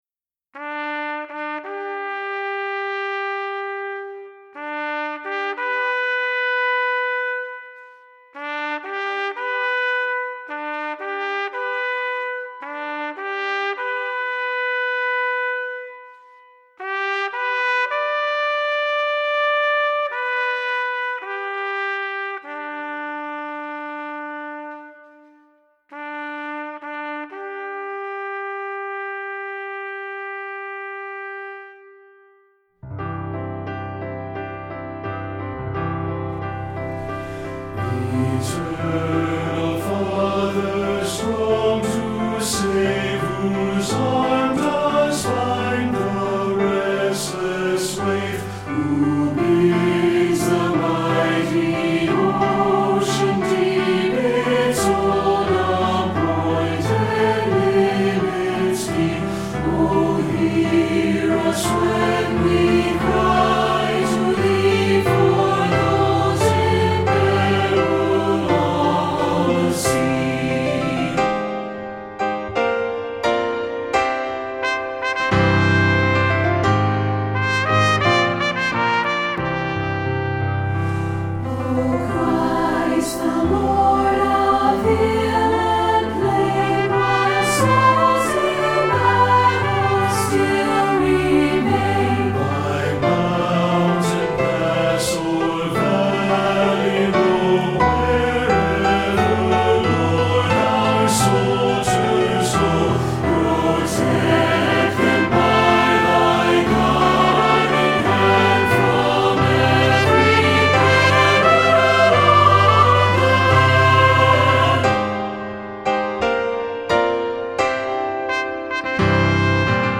Voicing: SATB and Trumpet